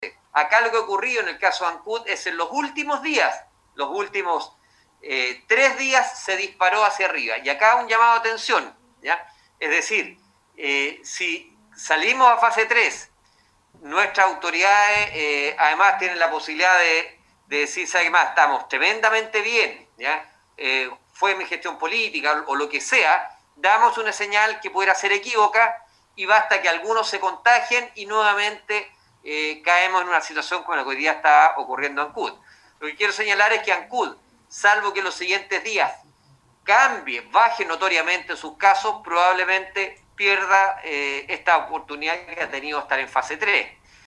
El seremi de Salud Alejandro Caroca llamó la atención acerca de señales equivocadas que pueden dar con sus declaraciones las autoridades comunales, por lo que es necesario alinearse en un solo objetivo, que es detener esta alza de casos.